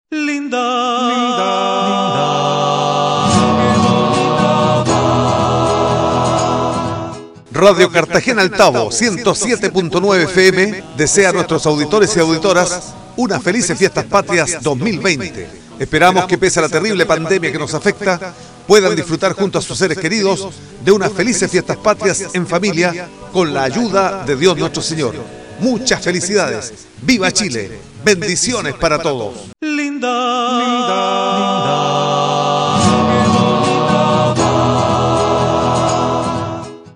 mp3-CARTAGENA-1-CON-MUSICA.mp3